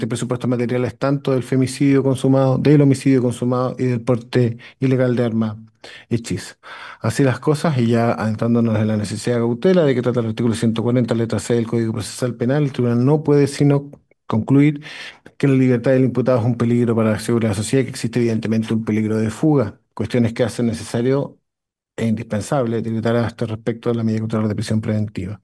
Todo esto fue relatado en el Tribunal de Garantía de Puerto Montt, donde el juez, Cristián Alfonso, decretó la prisión preventiva.